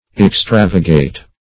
Search Result for " extravagate" : The Collaborative International Dictionary of English v.0.48: Extravagate \Ex*trav"a*gate\, v. i. [Pref. extra- + L. vagatus, p. p. of vagari to rove.